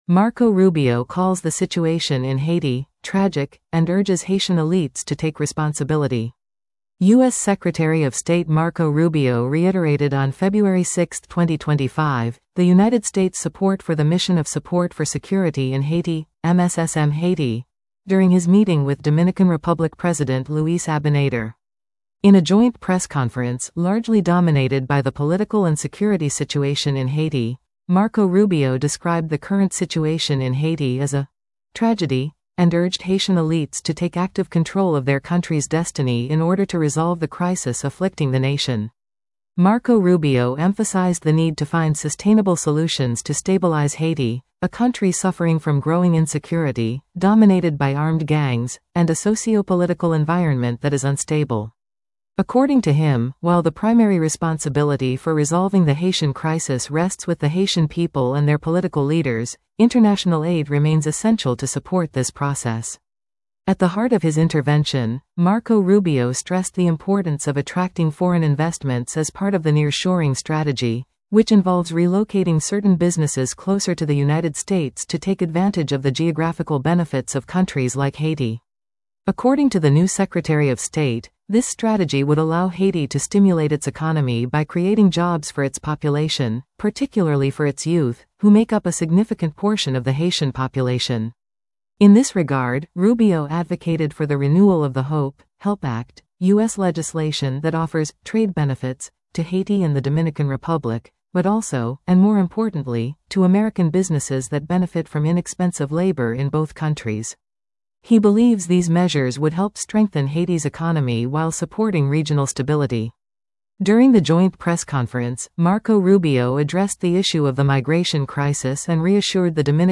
In a joint press conference, largely dominated by the political and security situation in Haiti, Marco Rubio described the current situation in Haiti as a “tragedy” and urged Haitian elites to take active control of their country’s destiny in order to resolve the crisis afflicting the nation.